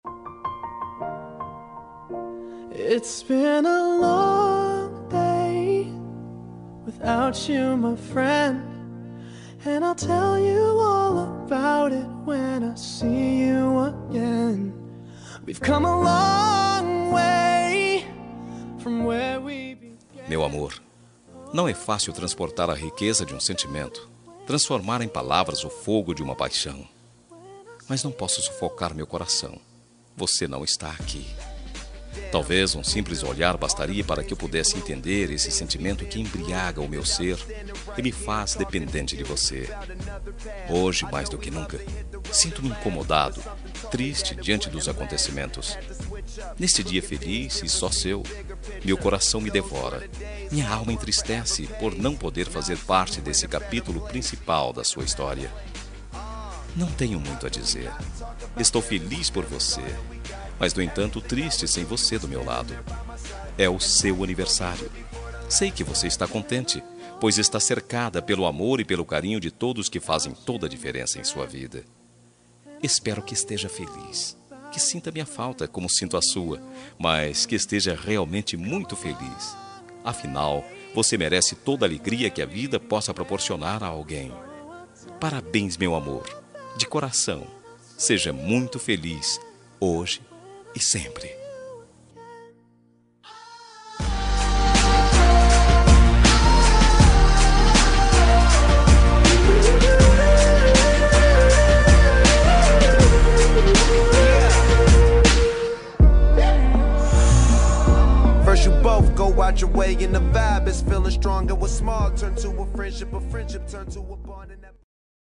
Aniversário Distante – Romântica – Masculina – Cód: 8899